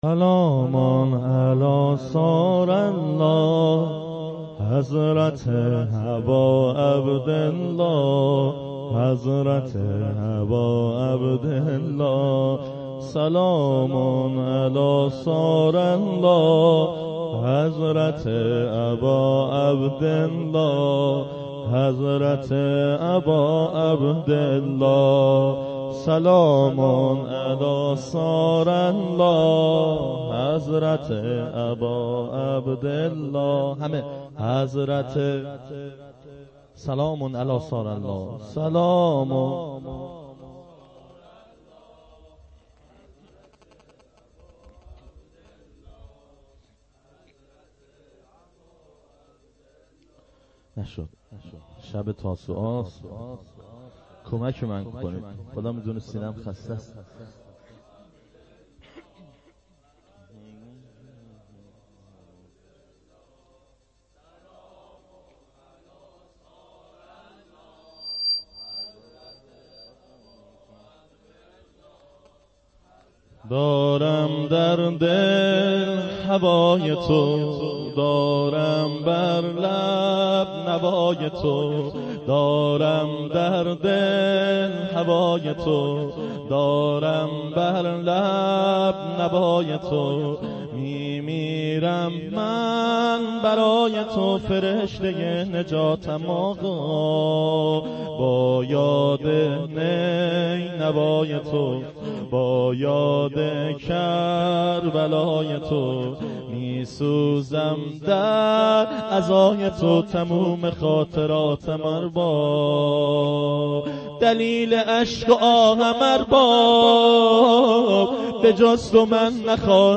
شب تاسوعا 1436| یک شنبه 93/8/11 | روضه حضرت ابوالفضل علیه السلام :: هیئت عاشورائیان شهرستان قائمشهر
مداحی